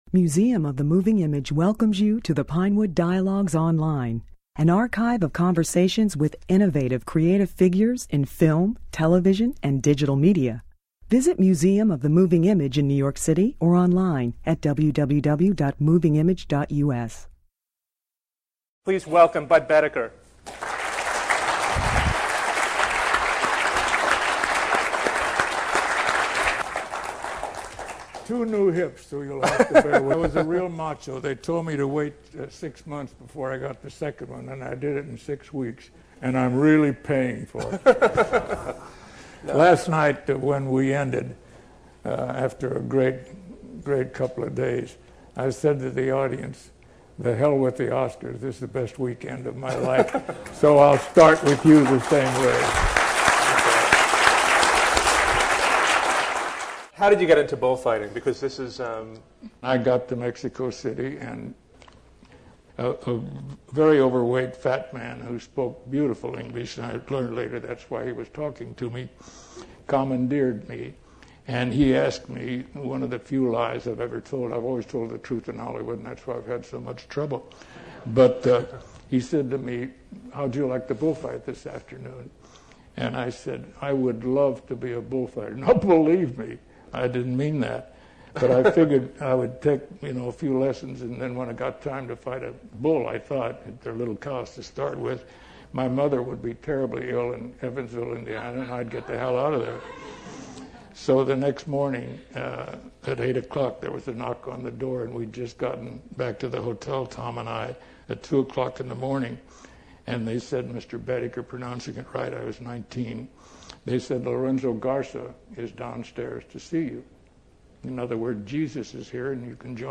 In conjunction, Moving Image presented a retrospective of Boetticher's films; this was one of his last personal appearances before his death in 2001.